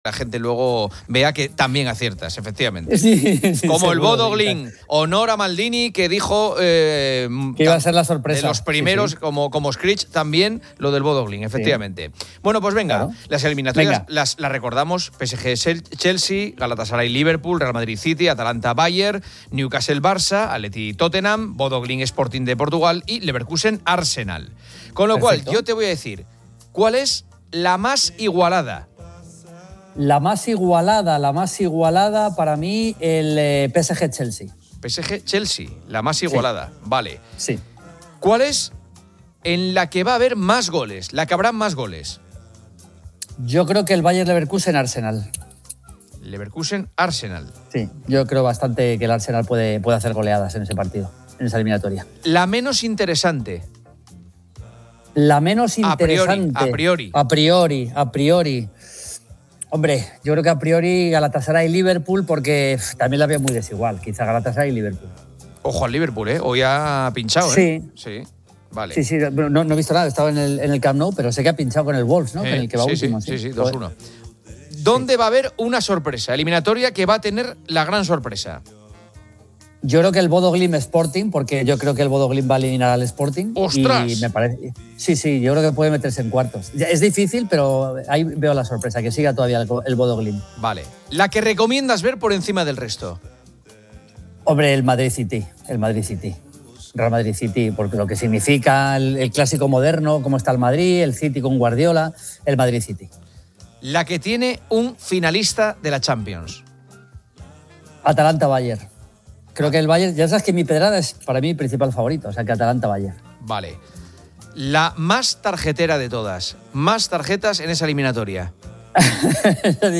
El programa invita a la audiencia a compartir sus hábitos al comer.